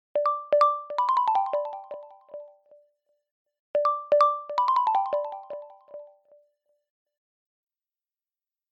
Ringtones Category: Message